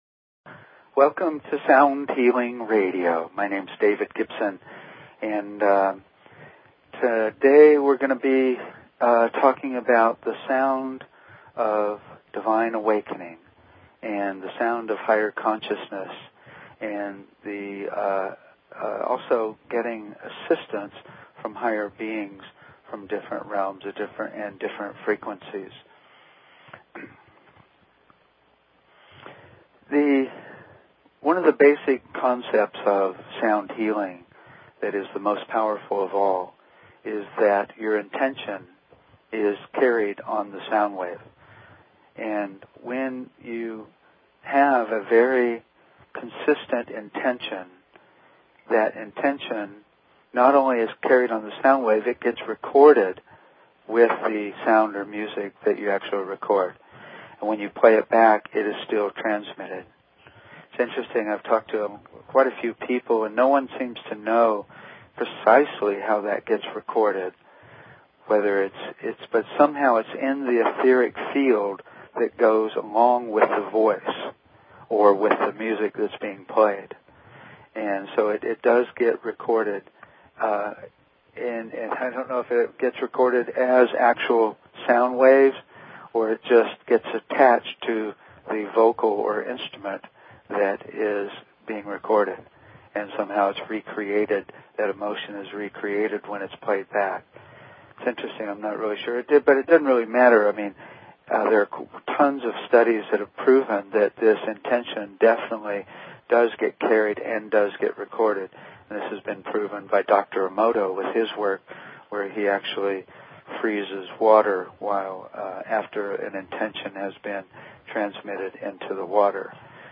Talk Show Episode, Audio Podcast, Sound_Healing and Courtesy of BBS Radio on , show guests , about , categorized as
The music is "Awakening" a recording that was created based on stillness, love and light. The tempo of the song is based on a really slow breath.